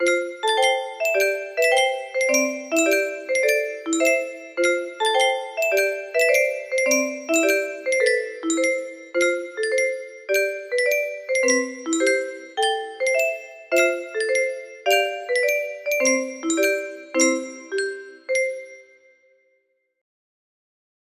Flavourful music box melody